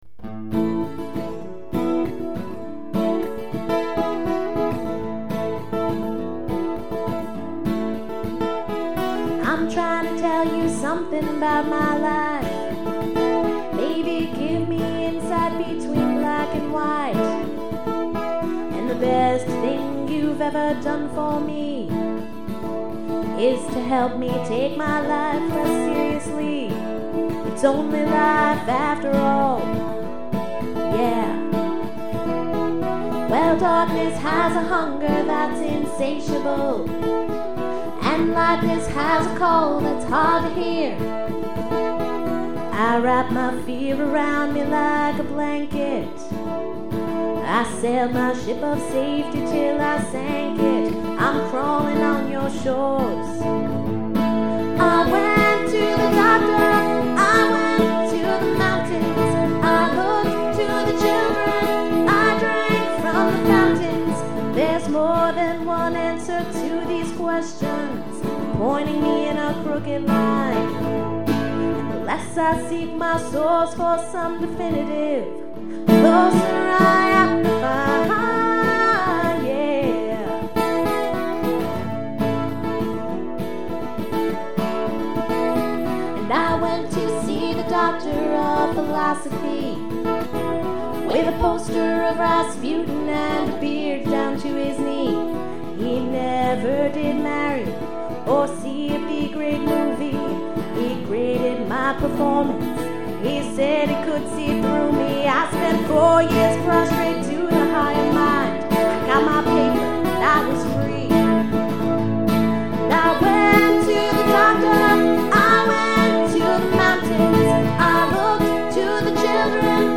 All songs recorded in May 2002 in Dallas, Texas